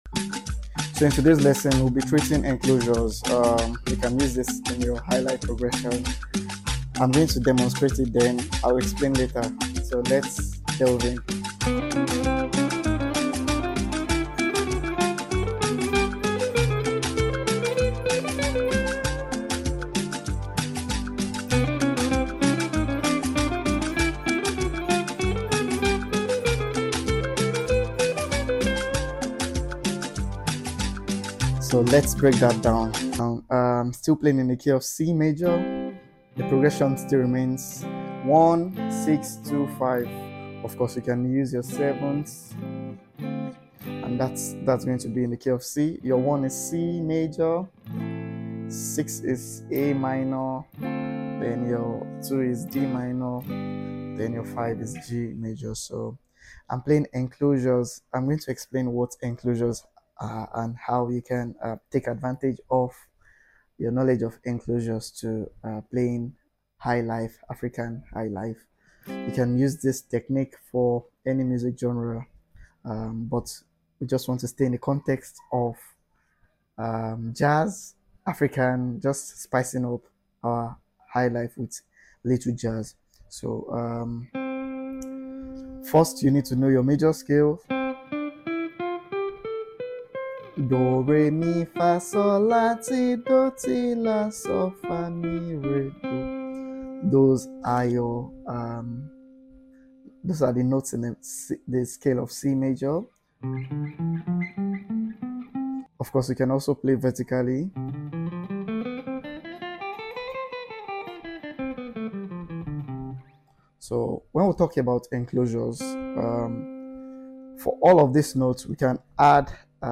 Highlife Guitar For Beginners Part